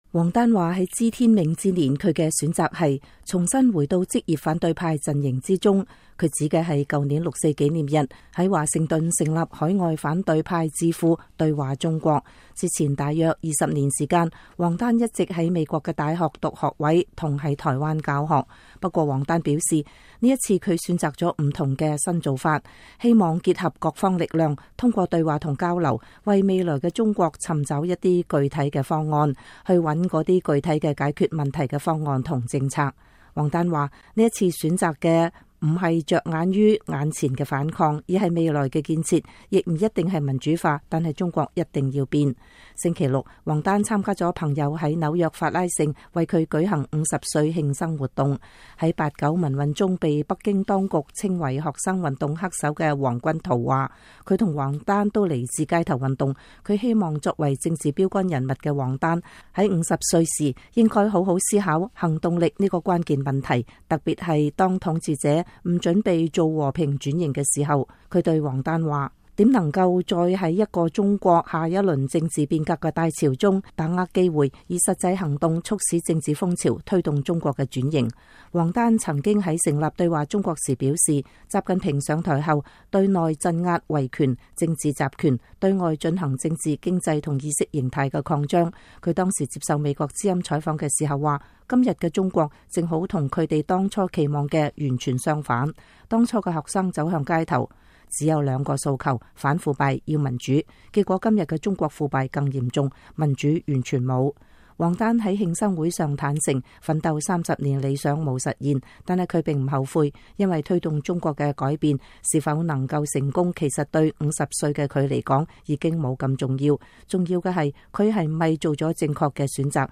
王丹在慶生會上
星期六，王丹參加了朋友們在紐約法拉盛為他舉行50歲慶生活動。